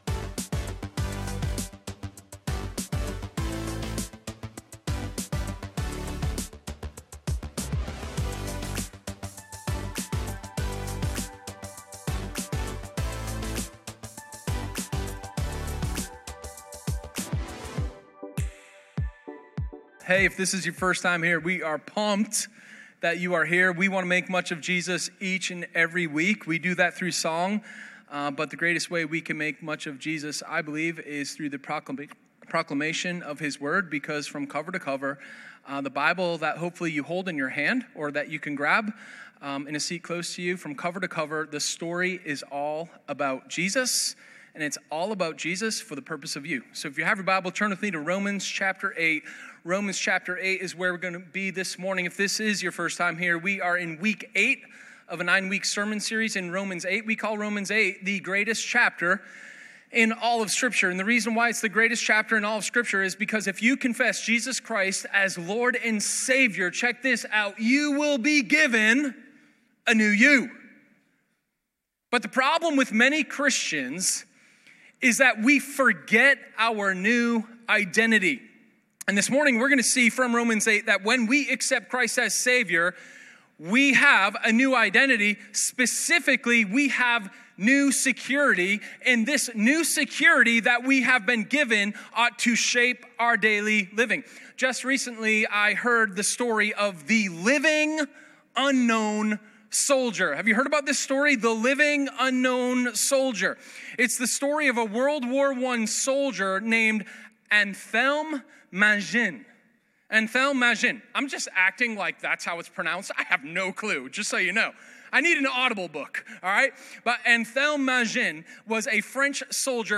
Sermon06_13_New-Security.m4a